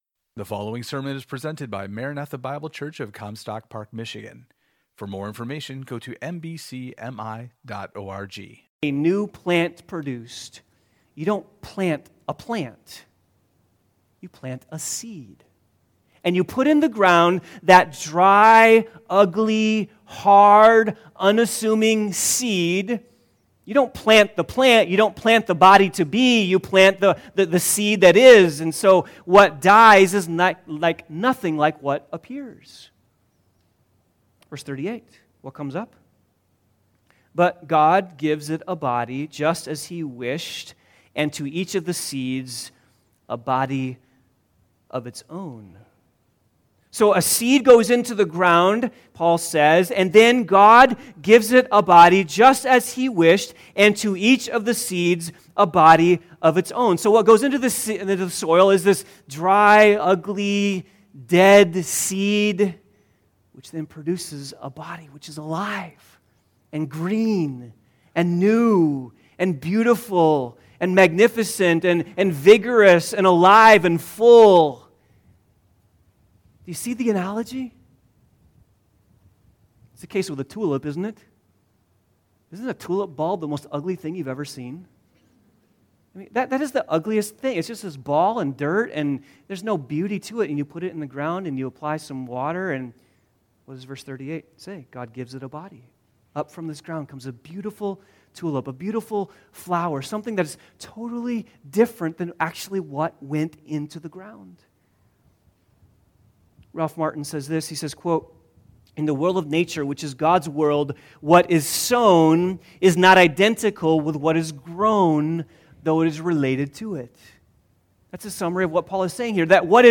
Due to a technical issue, the sermon audio is incomplete.